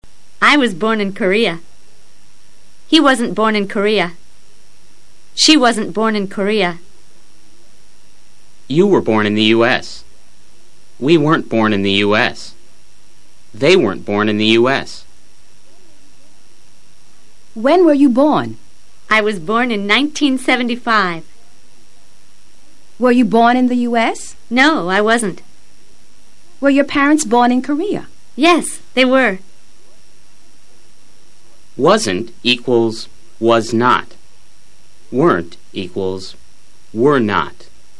Mr. Grammar explica el uso del verbo NACER. Escucha luego al profesor leyendo algunas oraciones e intenta repetirlo simultáneamente.